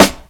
Alchemist Snare 5.wav